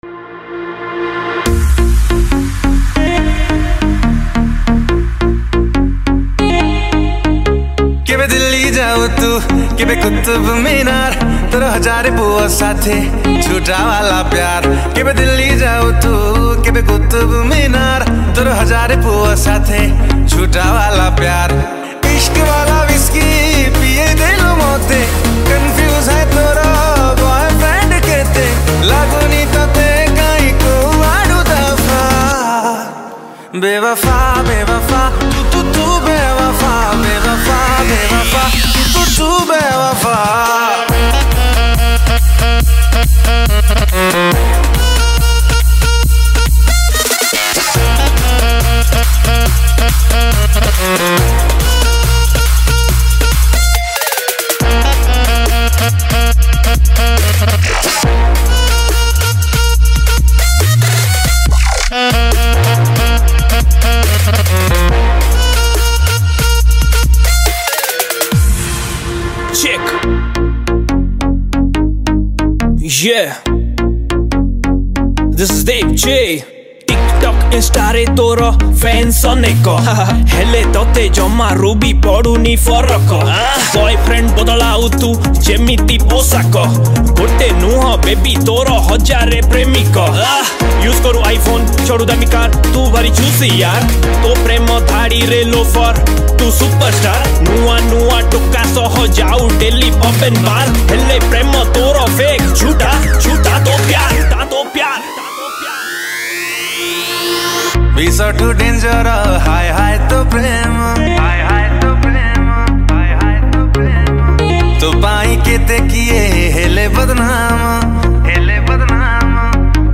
New Odia Dance Song